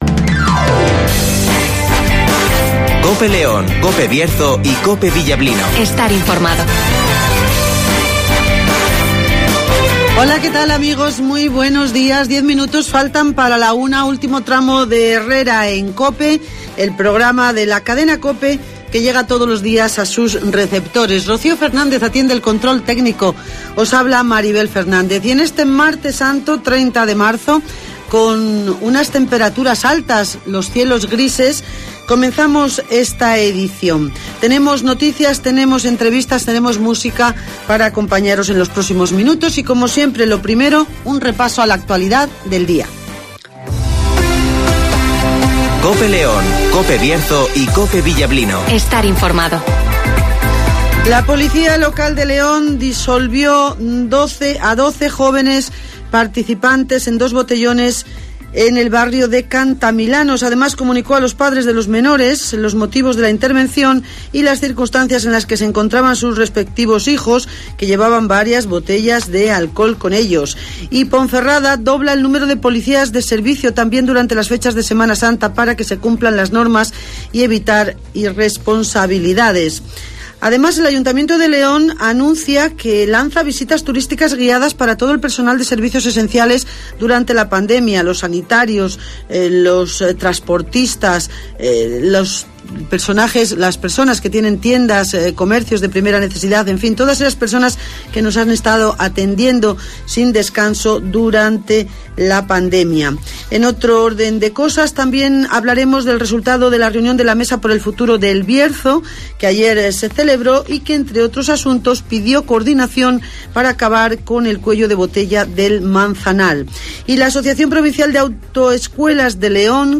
Avance informativo, El Tiempo (Neucasión) y Agenda (Carnicerías Lorpy)